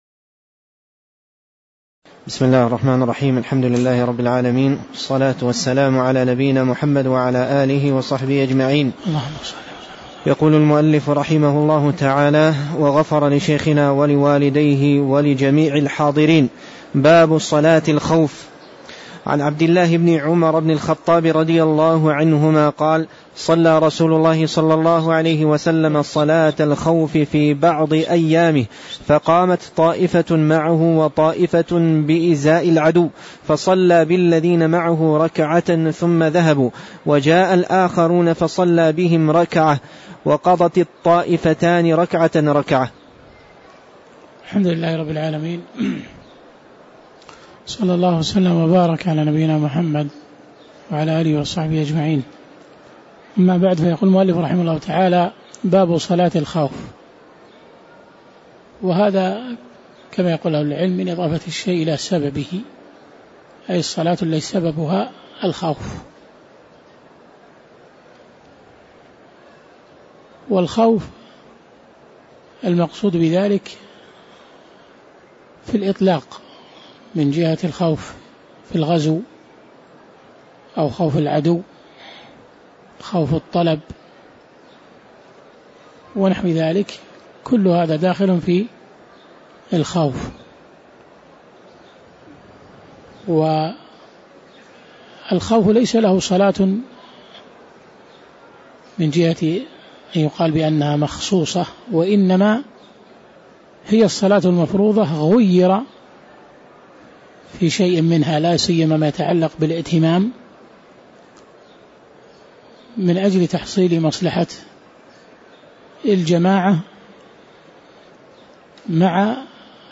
تاريخ النشر ٢٥ رجب ١٤٣٧ هـ المكان: المسجد النبوي الشيخ